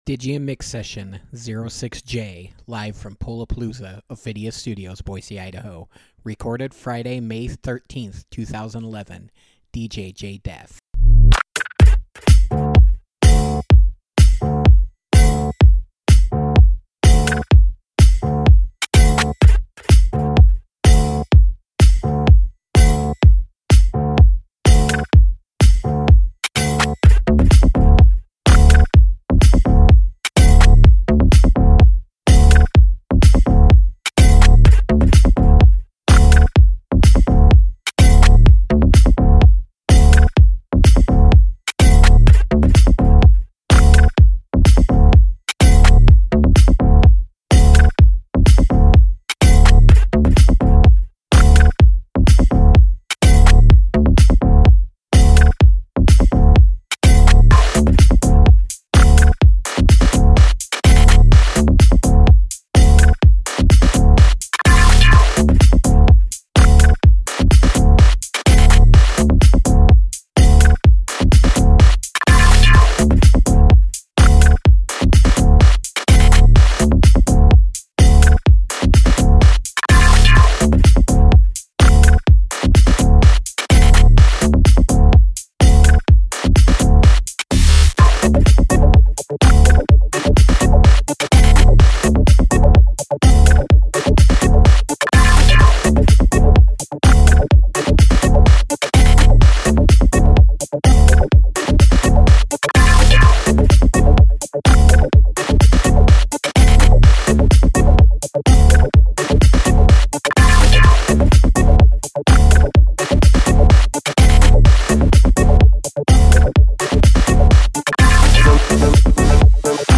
Tagged House